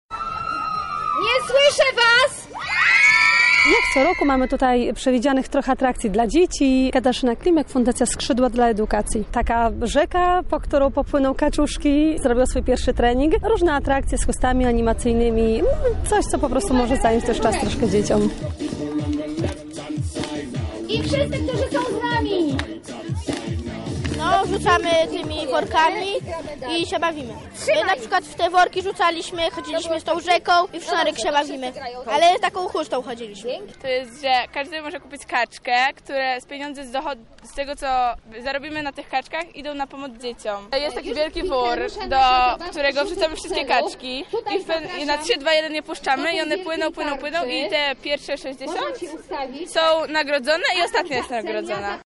Na Placu Litewskim jego organizatorzy namawiali Lublinian do udziału: